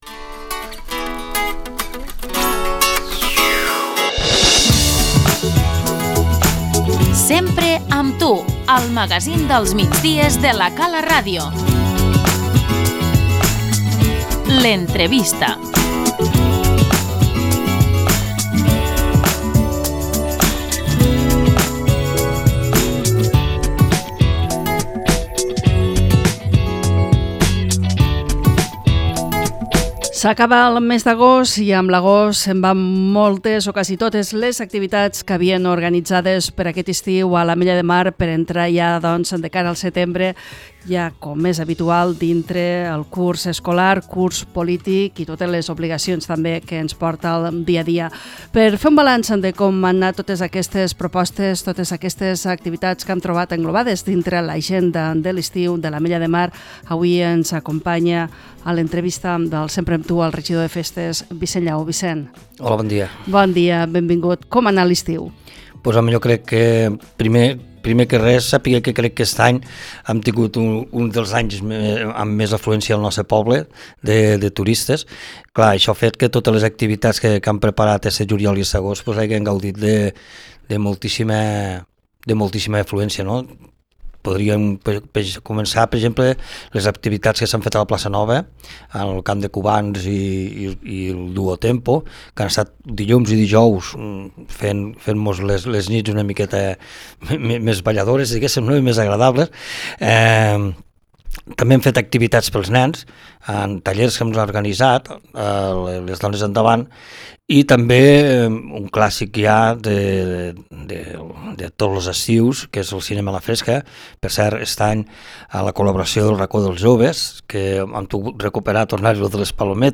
L'entrevista - Vicenç Llaó, regidor de Cultura i Festes